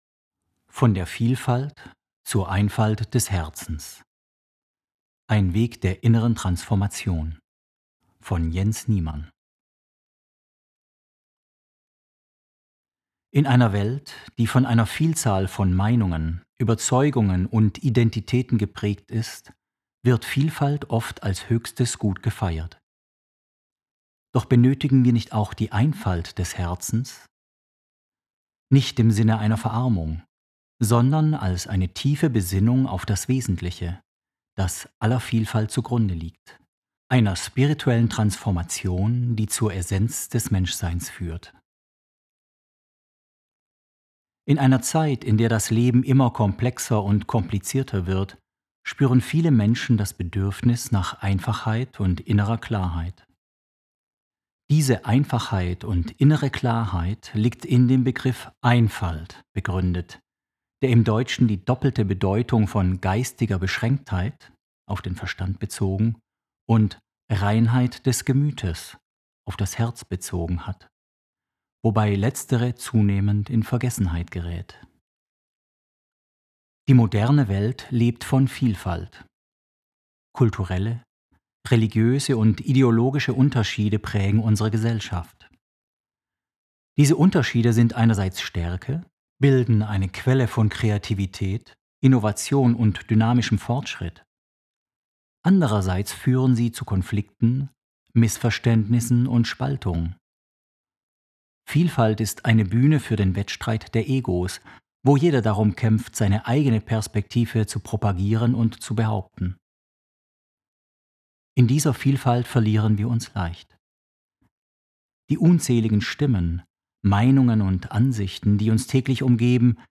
Logon Artikel gelesen